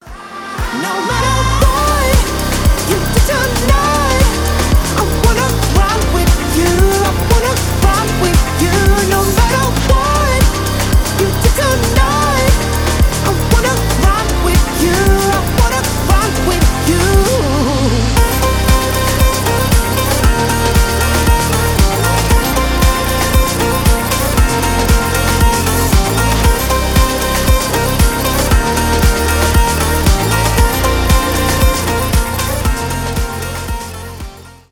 поп , танцевальные
dance pop